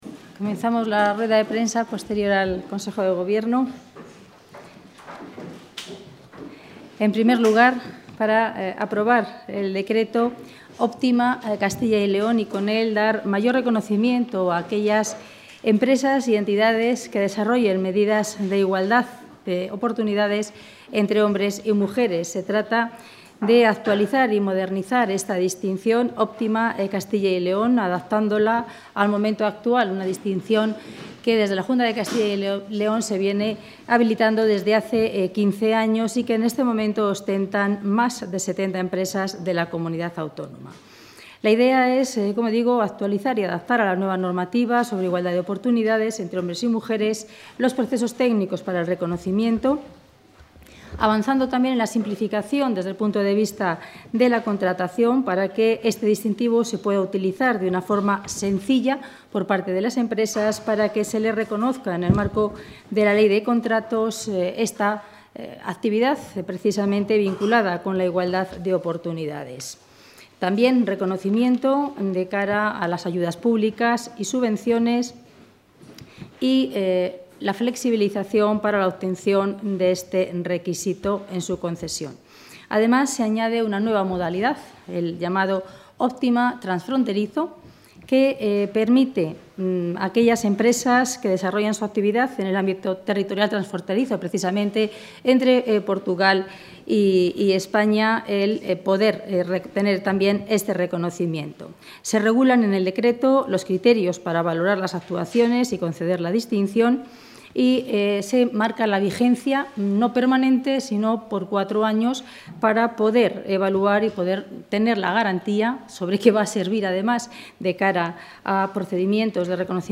Rueda de prensa posterior al Consejo de Gobierno.